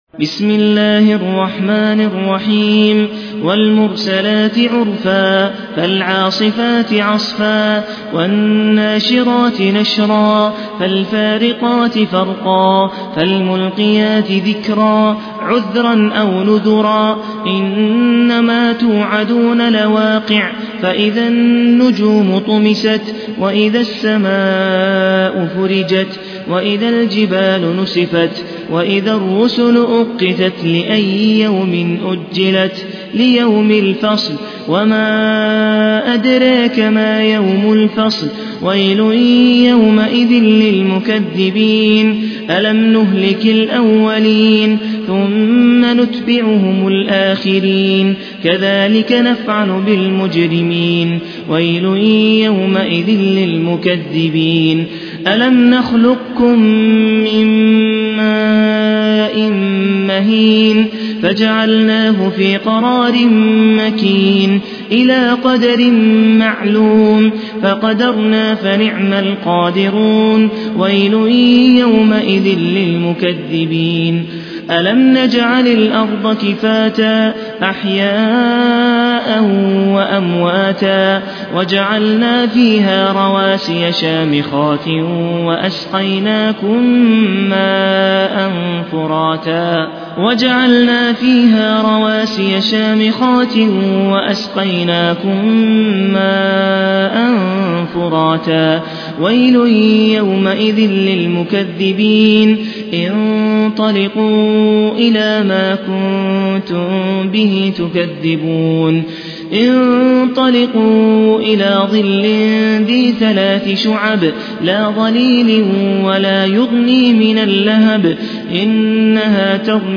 المصحف المرتل - شعبة عن عاصم بن أبي النجود